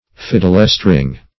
Fiddlestring \Fid"dle*string`\, n.